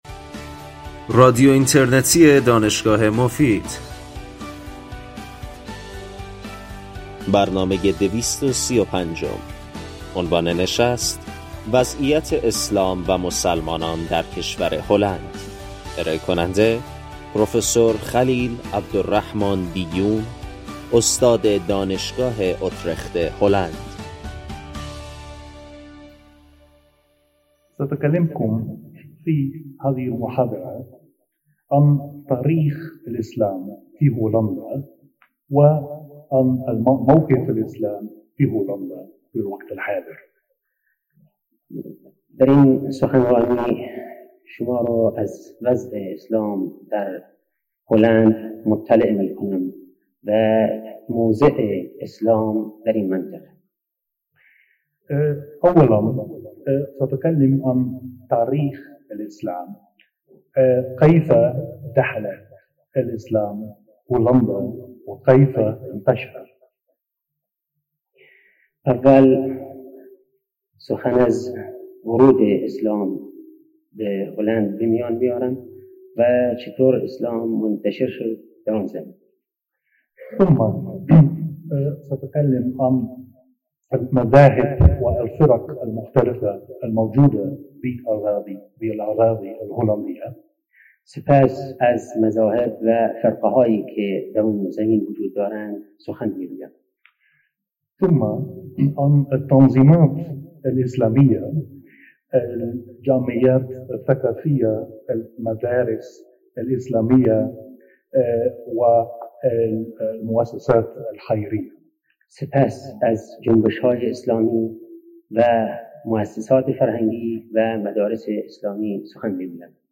این سخنرانی در سال ۱۳۷۹ و به زبان عربی همراه با ترجمه فارسی ایراد شده است.
بخش پایانی برنامه به پرسش و پاسخ اختصاص دارد.